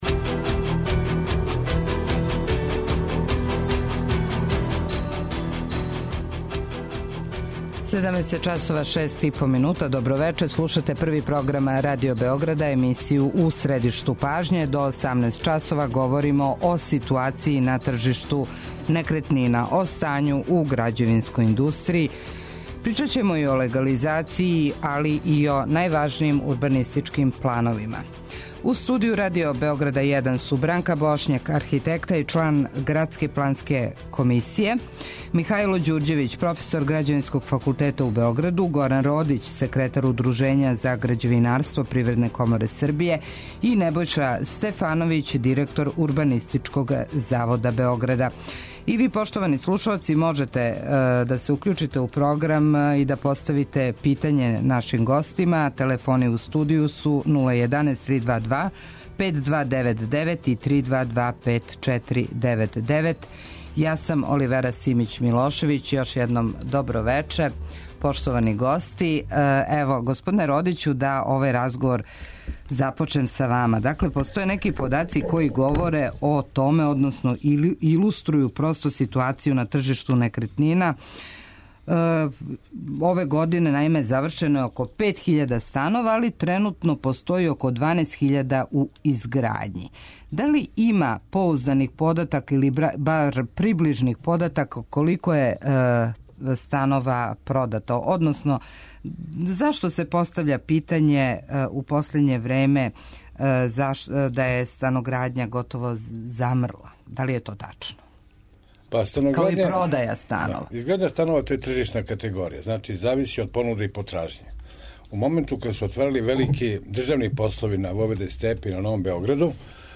И ви, поштовани слушаоци, можете постављати питања гостима емисије.